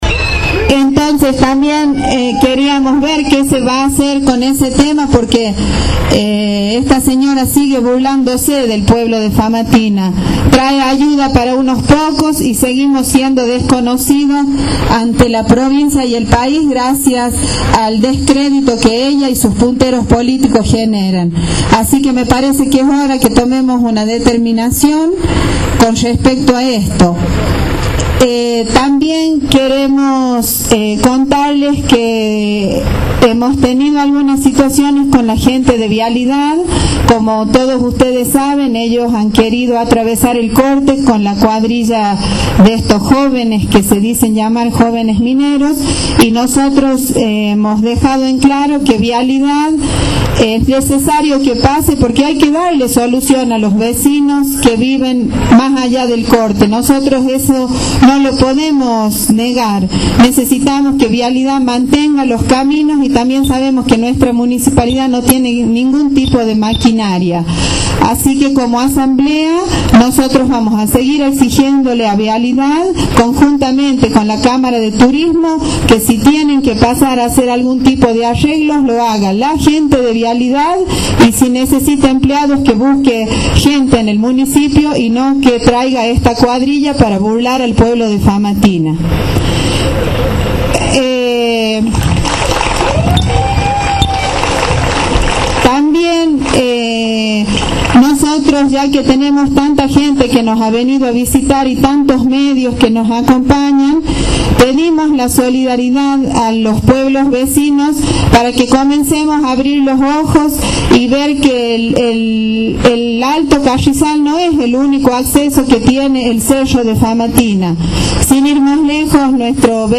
Cerca de las 21, comenzó la reunión frente a la Iglesia de San Pedro, próximo a la esquina de Avenida San Pedro y Presbítero Díaz.